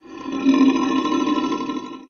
growl-1.ogg